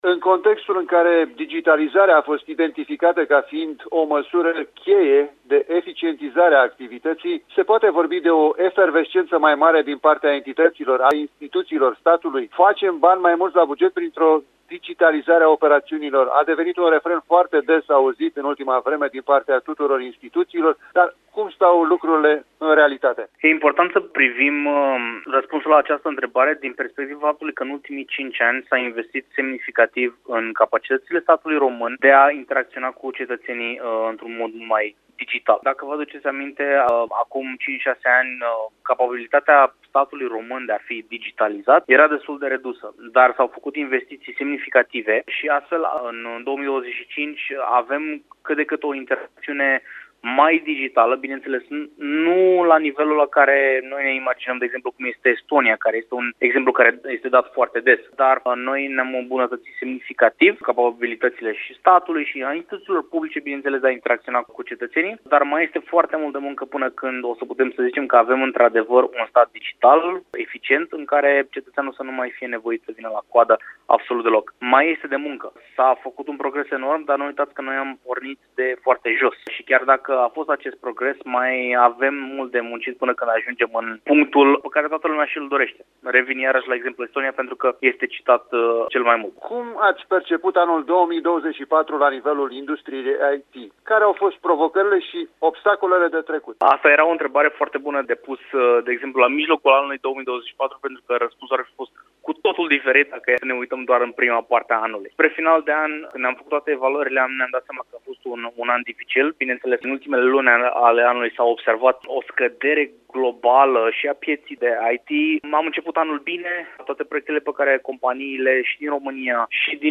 AUDIO | Impactul noilor măsuri fiscal-bugetare în sectorul IT. Interviu